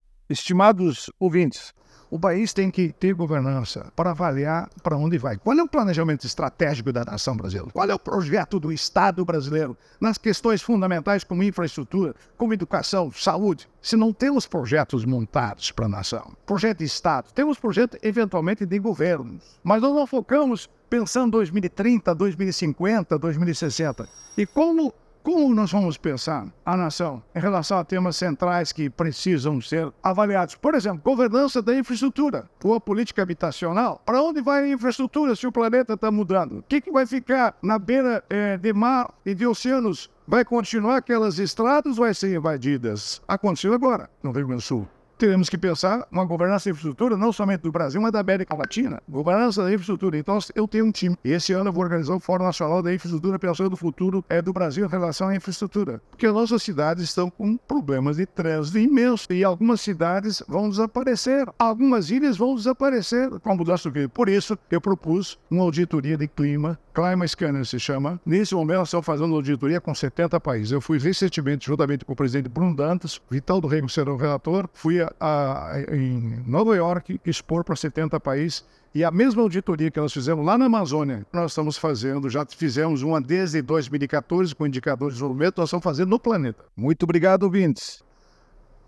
É o assunto do comentário do ministro do Tribunal de Contas da União, Augusto Nardes, desta terça-feira (06/06/24), especialmente para OgazeteirO.